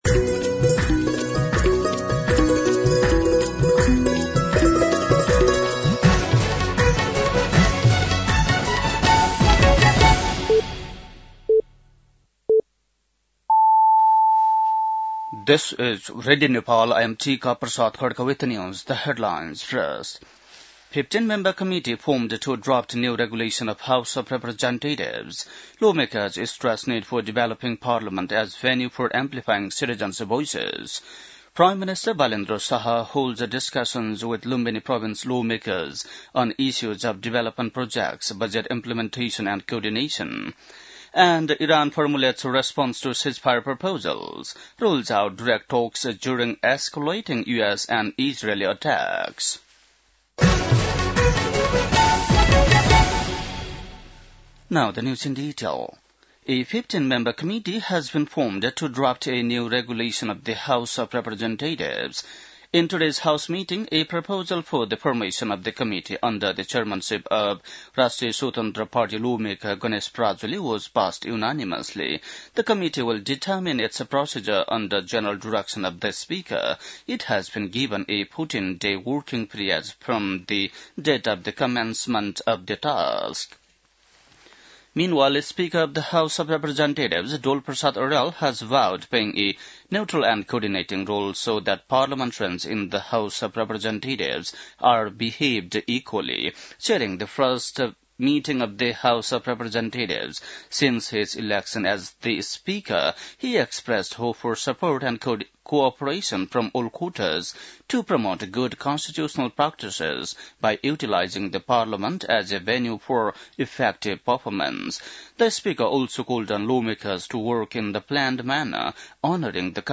बेलुकी ८ बजेको अङ्ग्रेजी समाचार : २३ चैत , २०८२
8-pm-news-.mp3